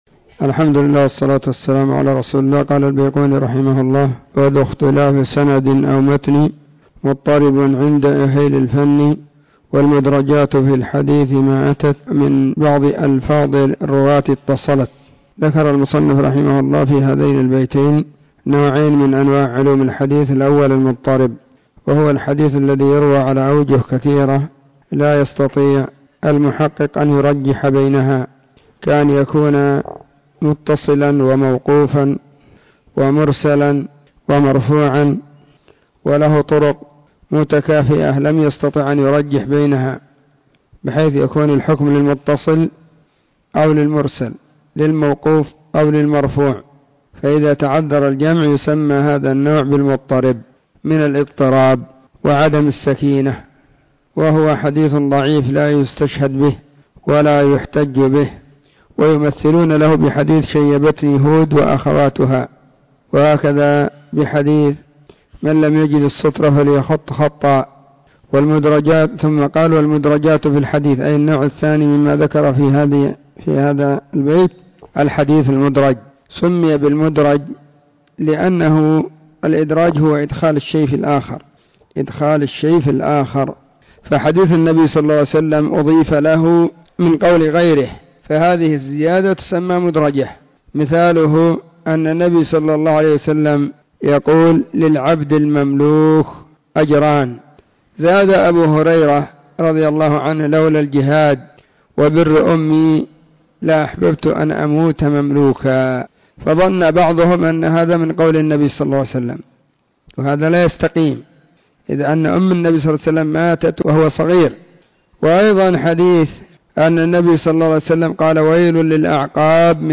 الدرس 22- من التعليق المختصر على المنظومة البيقونية.
📢 مسجد الصحابة – بالغيضة – المهرة، اليمن حرسها الله.